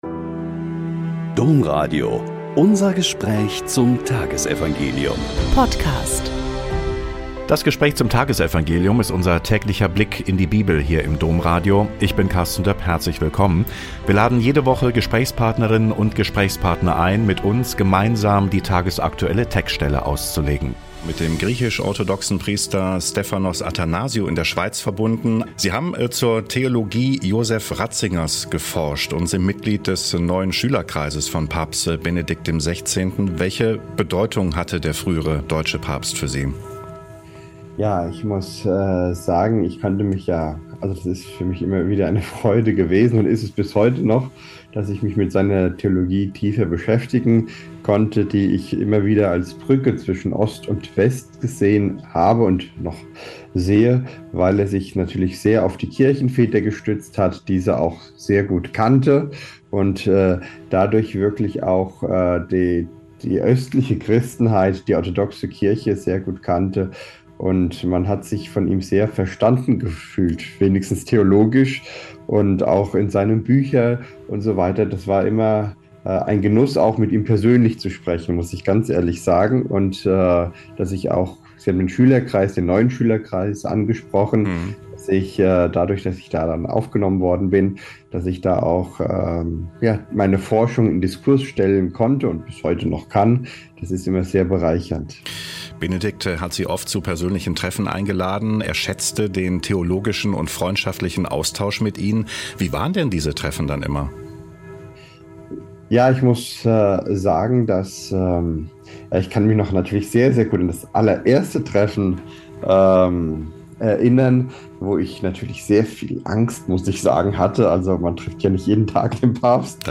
Mt 22,34-40 - Gespräch